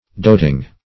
Doting \Dot"ing\, a.